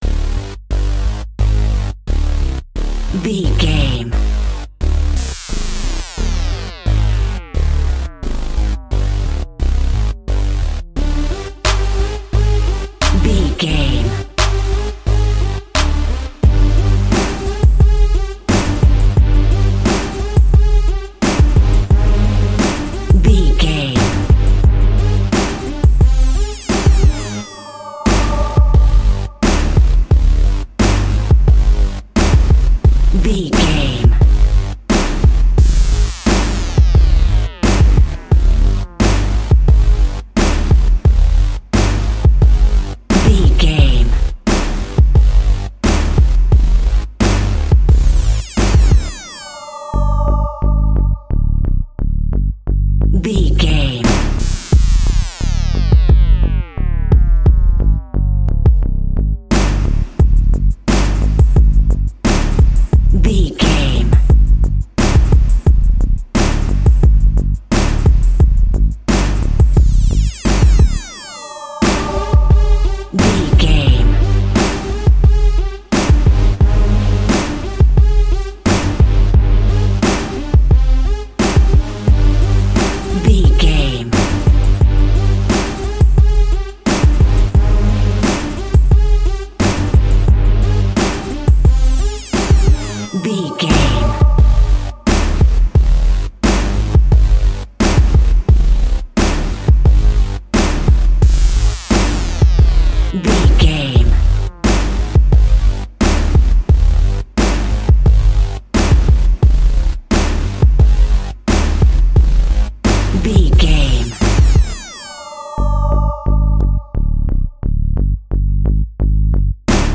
Locrian
D
drums
percussion
strings
conga
brass